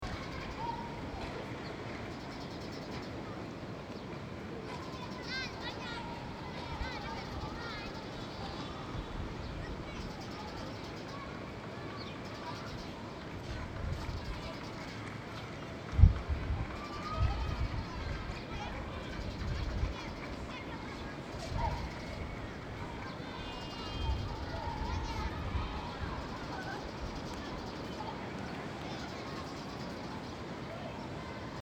Je vais donc sur la terrasse peindre le mausolée dans la brume, et écouter la ville qui se réveille.